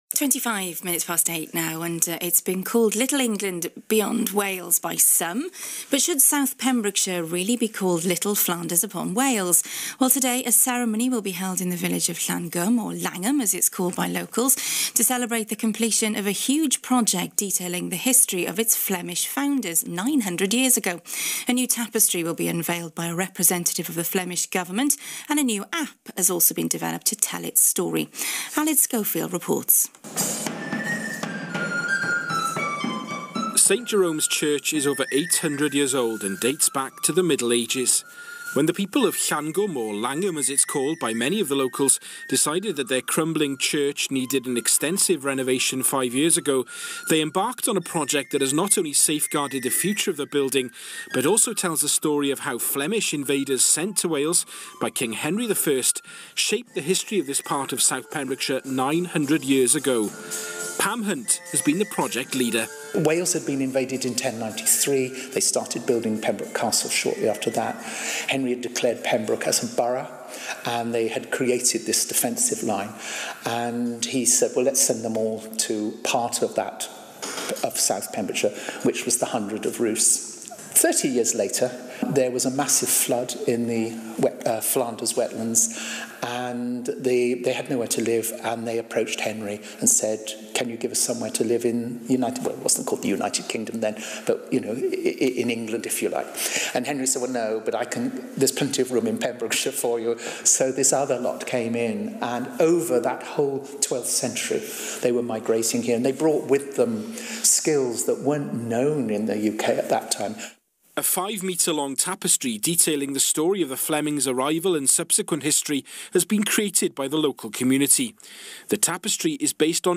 BBC Radio Wales, Good Morning Wales - Interview